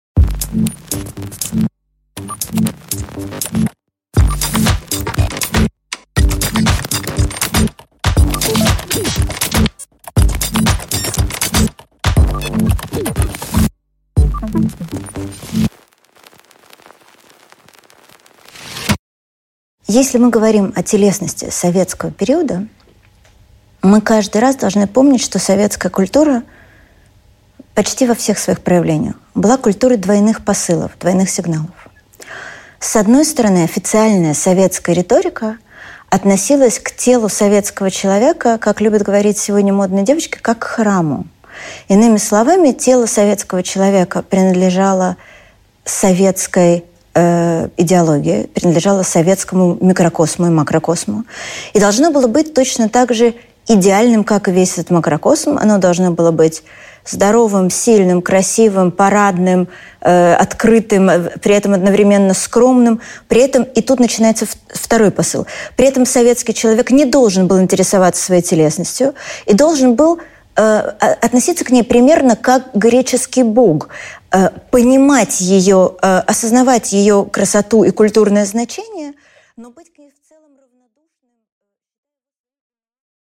Аудиокнига Постсоветское отношение к телу и одежде | Библиотека аудиокниг